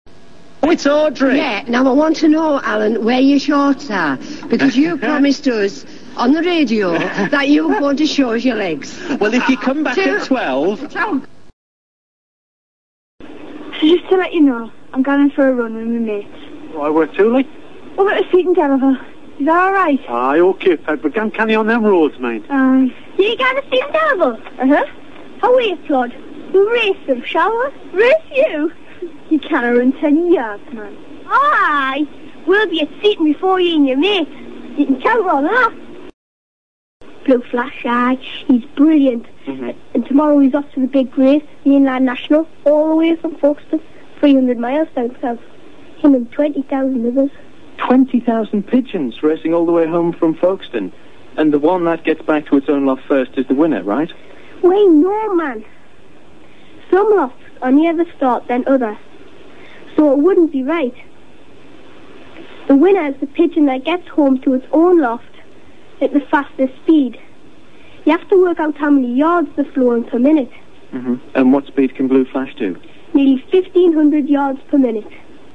Northern English
The north of England is somewhat more conservative in its phonology compared to the south and has not gone through many of the changes found in the latter area. The two most obvious of these are (1) the lowering of early modern English /u/ to /ʌ/, i.e. northern speakers pronounce cut as [cʊt], (2) the lengthening of low vowels before voiceless fricatives, i.e. northern speakers say [pas] for [pɑ:s] pass.
England_Northern.wav